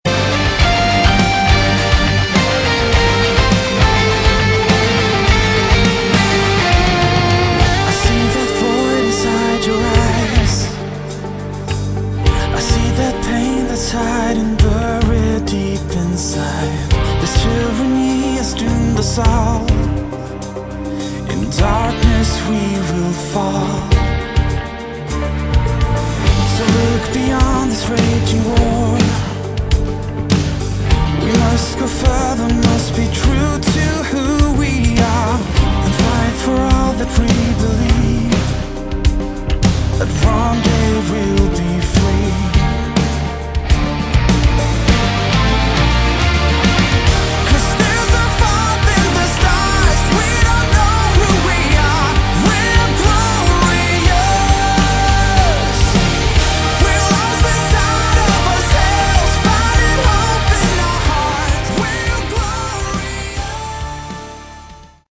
Category: AOR / Melodic Rock
lead vocals
lead guitar
rhythm guitar
keyboards
bass
drums